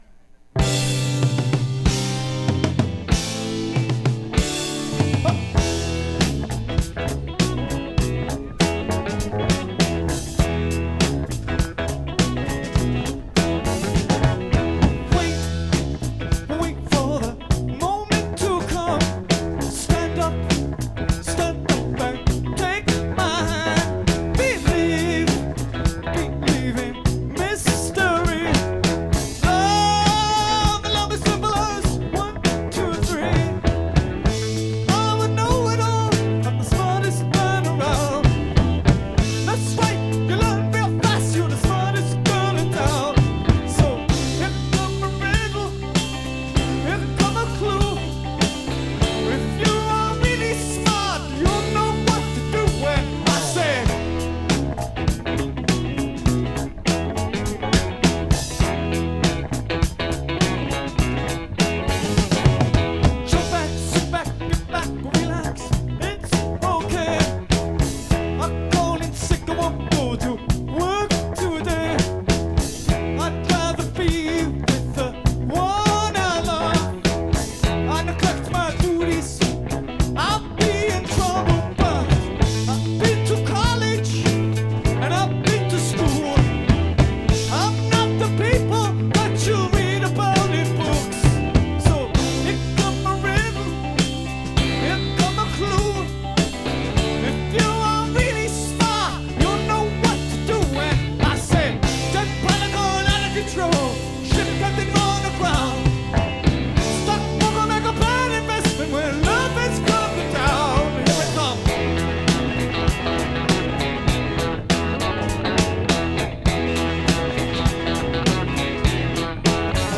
Recorded November 17th, 1977 in Massachusetts
Source: vinyl rip